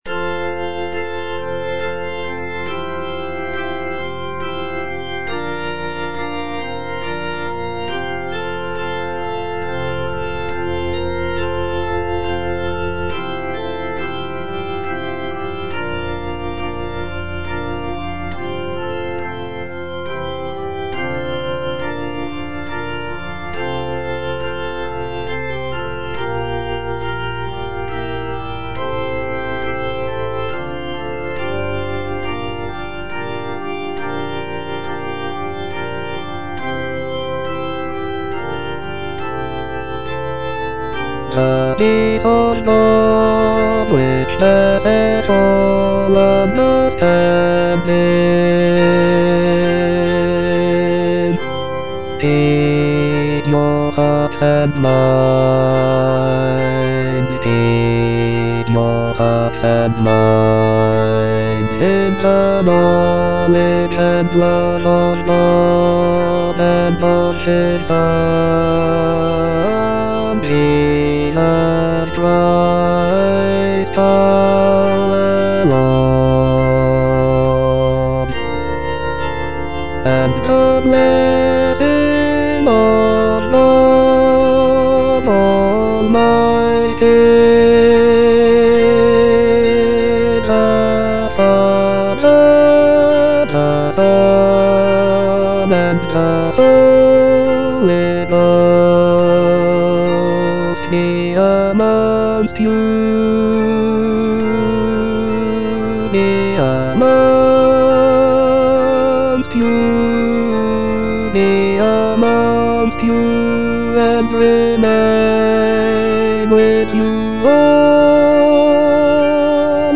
Bass (Voice with metronome)
a choral anthem